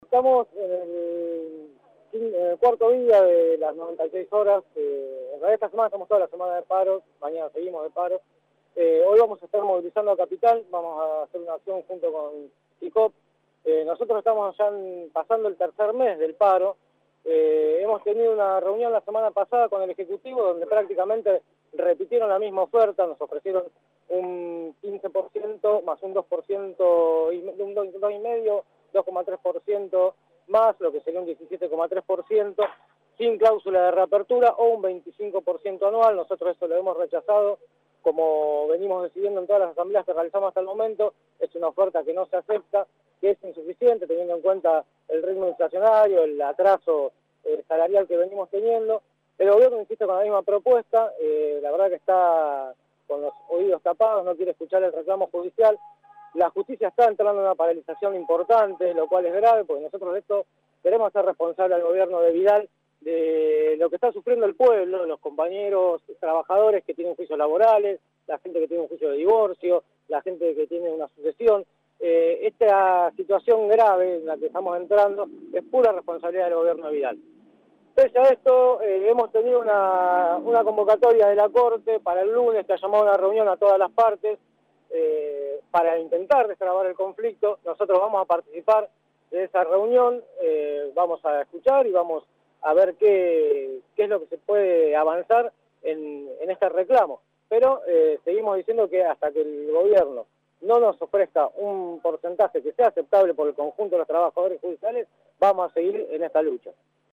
advirtió esta mañana en un diálogo con  La Redonda que "la Justicia está entrando en una paralización"